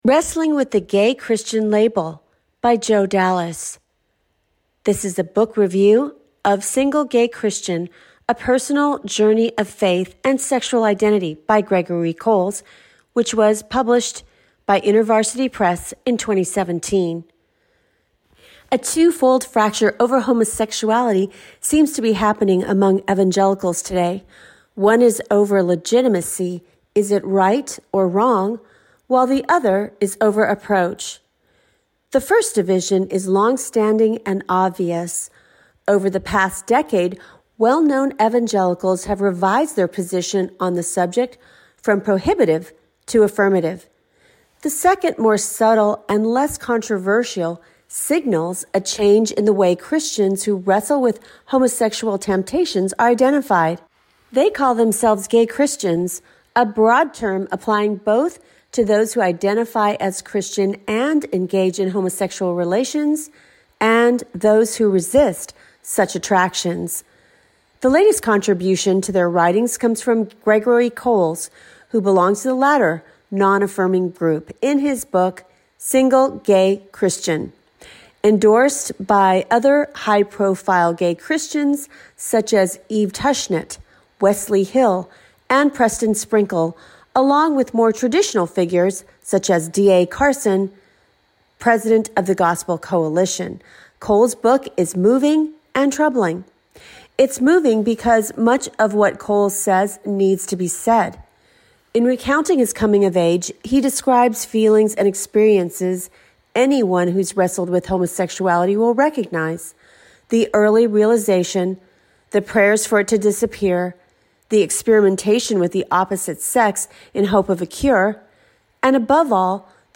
Listen to this article (5: 27 min)
CRJ-Reads-14-Wrestling-With-the-Gay-Christian-Label-article-only.mp3